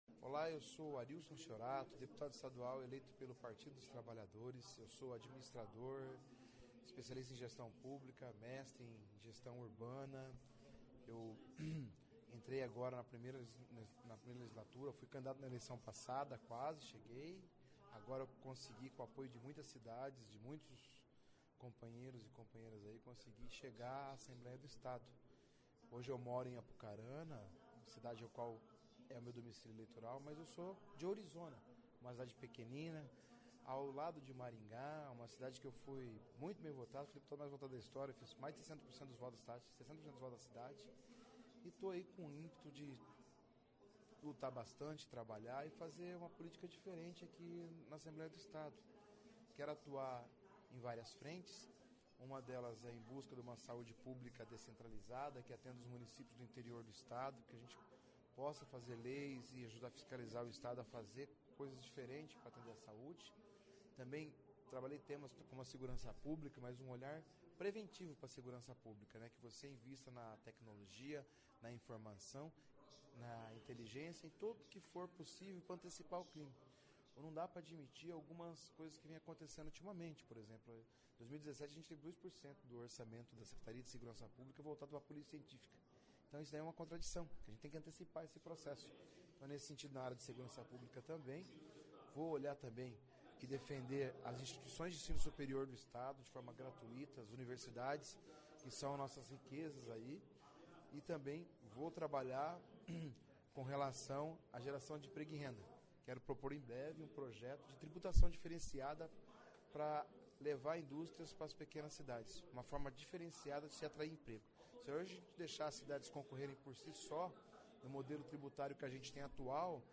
Ouça a entrevista com o parlamentar, que vem de Apucarana e chega à Assembleia  Legislativa pela primeira vez.